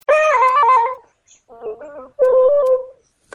Tags: auto tune